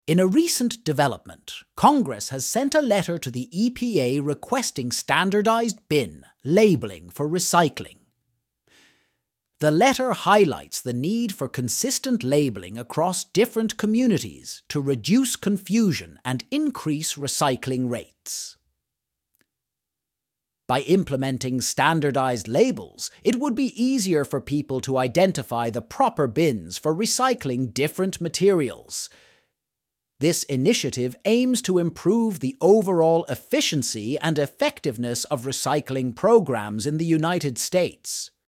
Quick Summary Audio